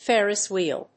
Fér・ris whèel /férɪs‐/
• / férɪs‐(米国英語)